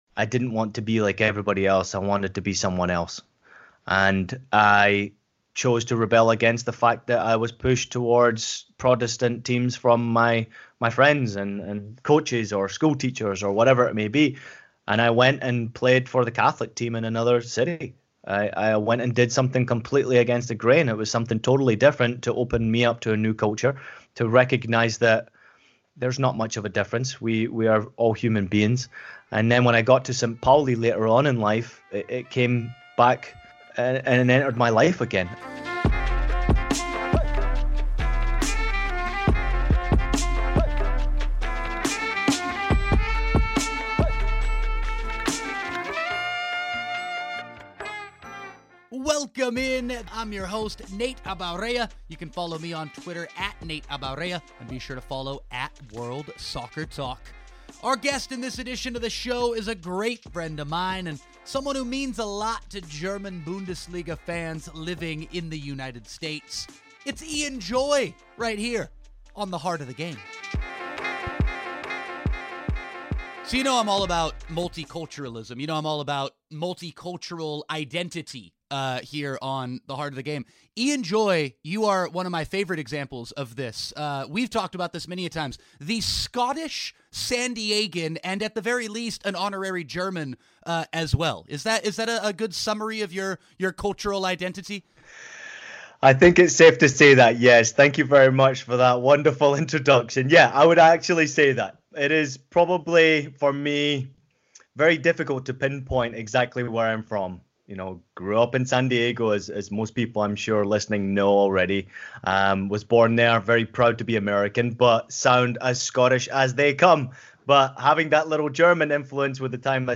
interview: The Heart Of The Game Podcast